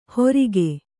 ♪ horige